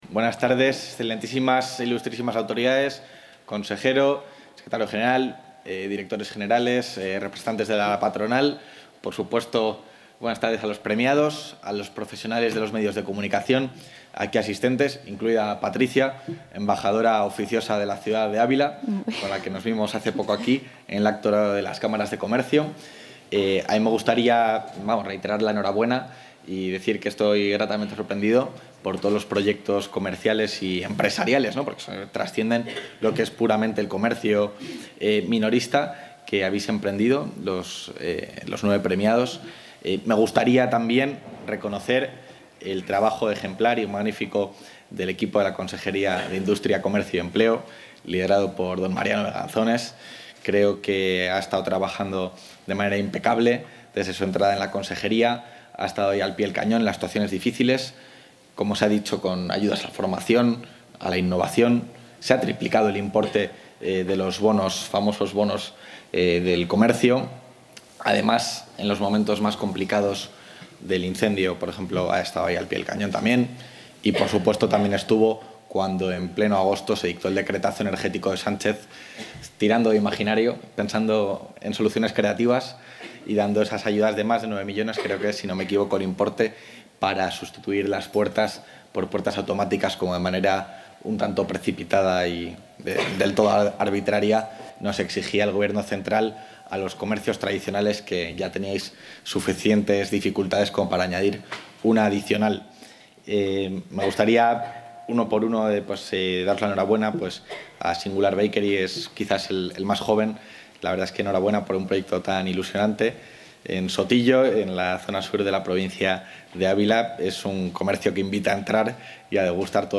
Intervención del vicepresidente.
El vicepresidente de la Junta de Castilla y León, Juan García-Gallardo, ha presidido esta tarde en el Palacio de Congresos Lienzo Norte de Ávila la gala de entrega de los ‘Premios de Comercio Tradicional de Castilla y León’ en su XV edición.